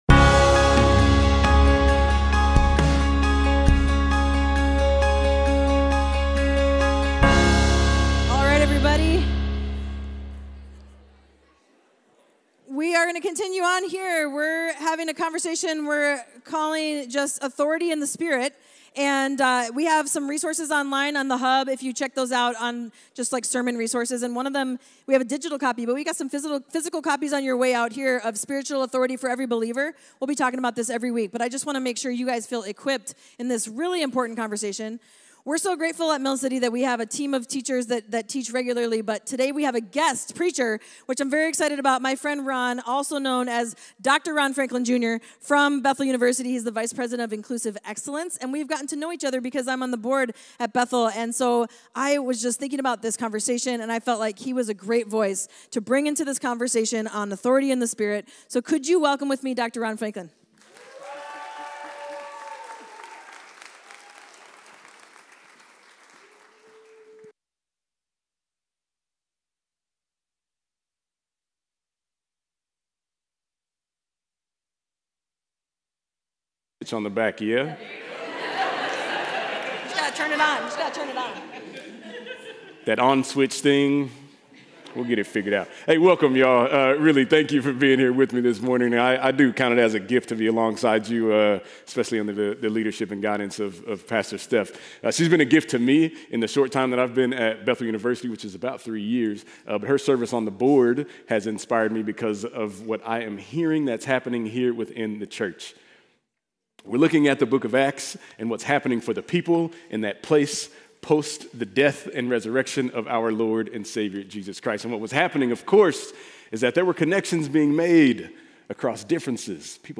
Mill City Church Sermons Authority in the Spirit: Empowered Aug 12 2024 | 00:37:52 1x 00:00 / 00:37:52 Subscribe Share RSS Feed Share Link Embed